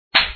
slap.mp3